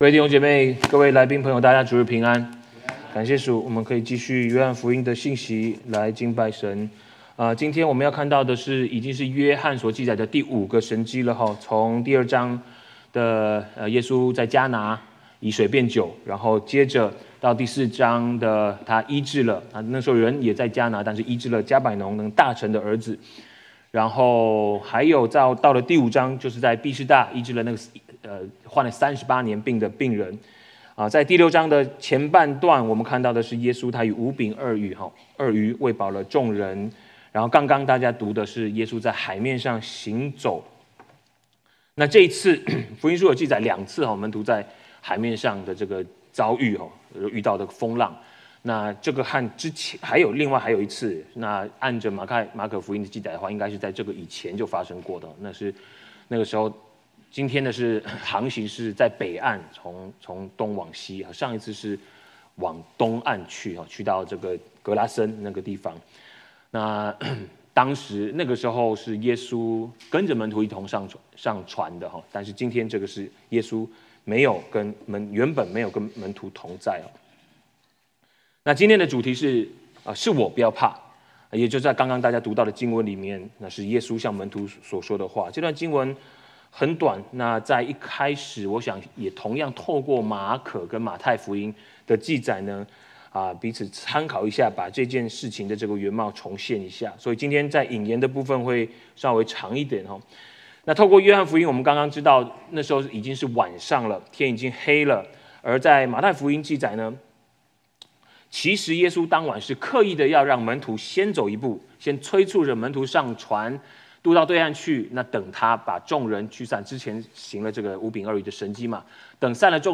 2025 主日证道 | Series | Chinese Baptist Church of West Los Angeles